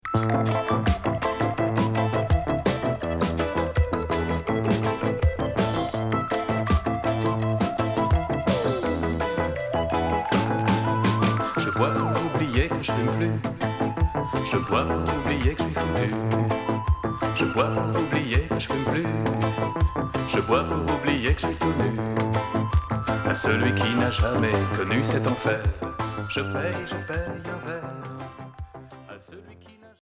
Reggae Unique 45t retour à l'accueil